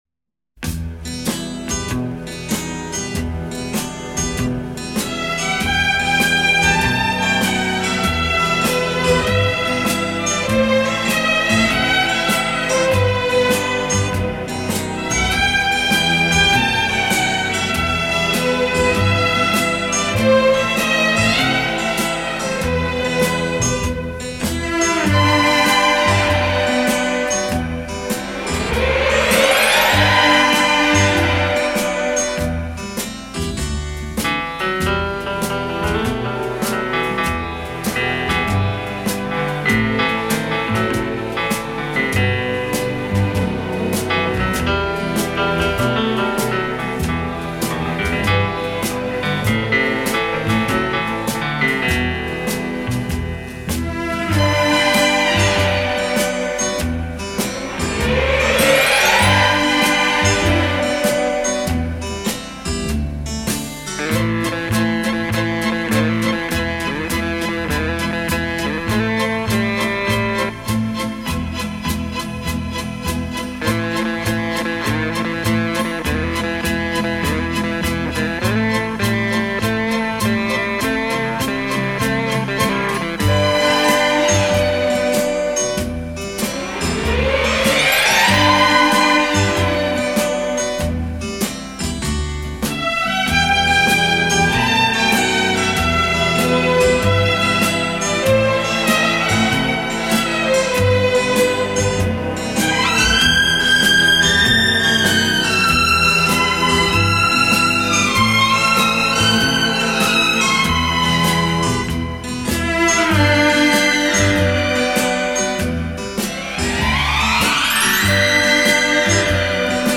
Genre:Instrumental